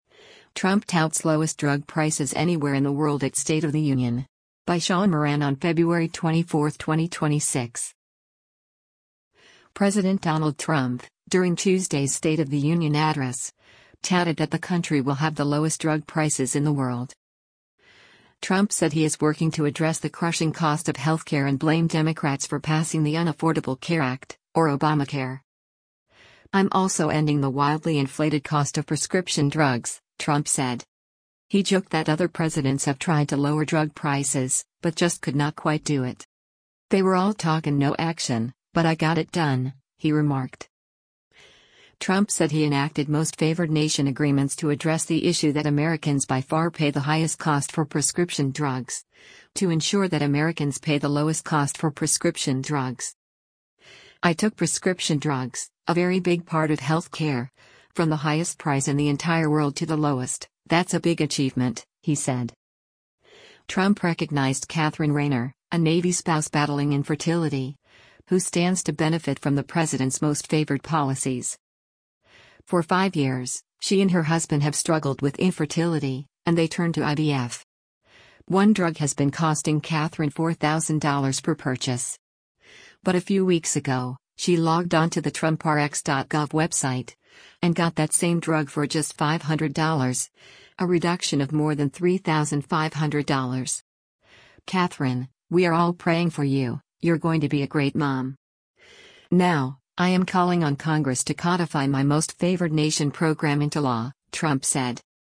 President Donald Trump, during Tuesday’s State of the Union address, touted that the country will have the lowest drug prices in the world.